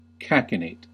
Ääntäminen
Synonyymit guffaw Ääntäminen US Tuntematon aksentti: IPA : /ˈkækɪneɪt/ Haettu sana löytyi näillä lähdekielillä: englanti Käännöksiä ei löytynyt valitulle kohdekielelle.